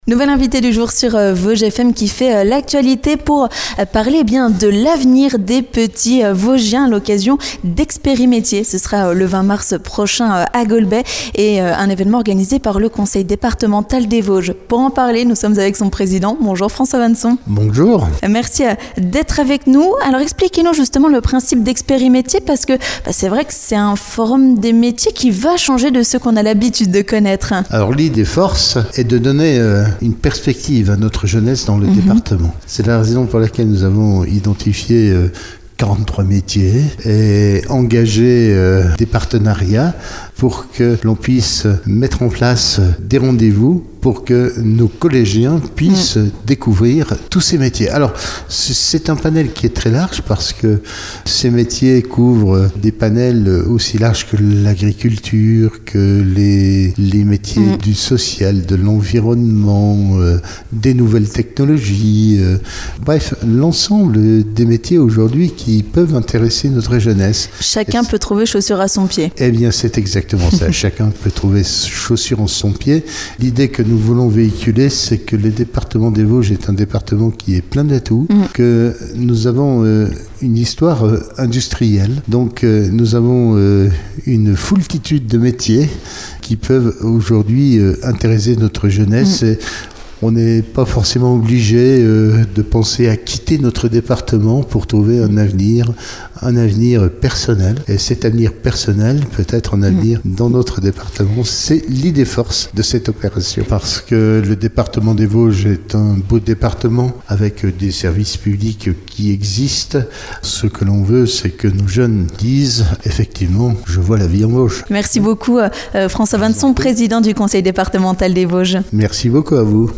3. L'invité du jour
Pour en parler, notre Invité du Jour est le président du Conseil départemental des Vosges, François Vannson.